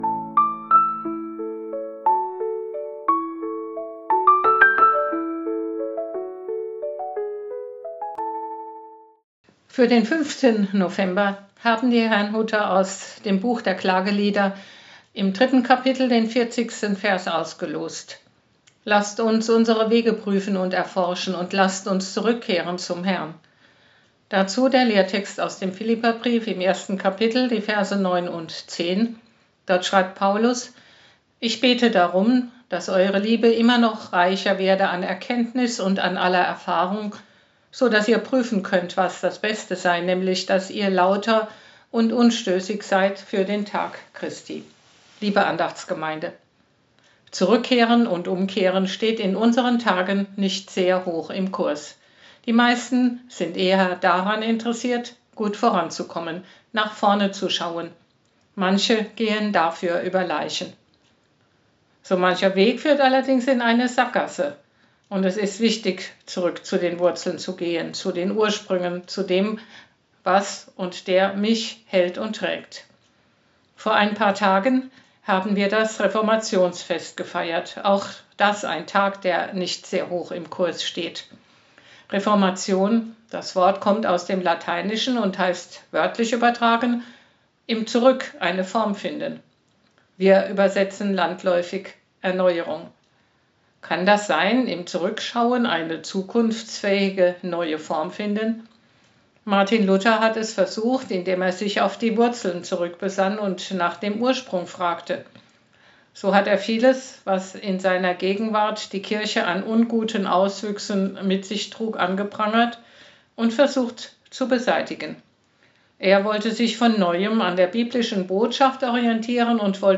Man kann die Andachten auch abonnieren über: